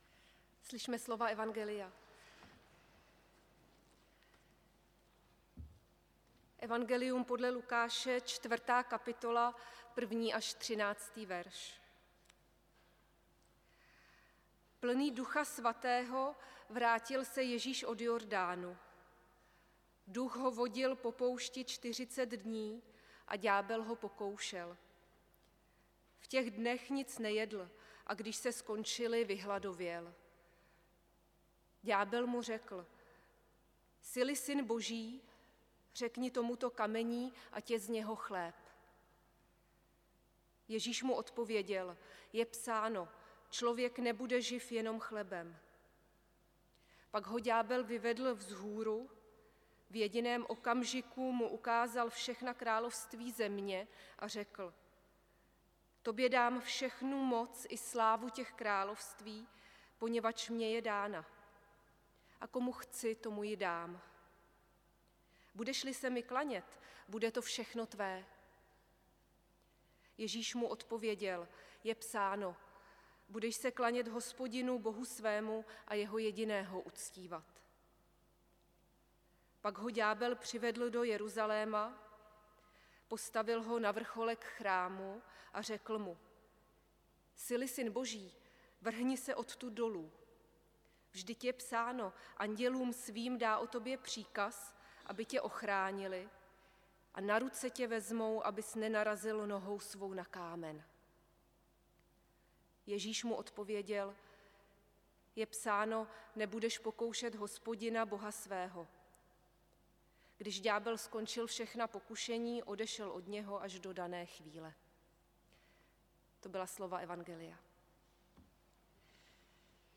audio kázání Lukáš 4. 1 – 13